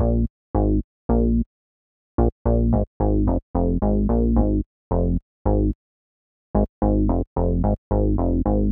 03 Bass PT3.wav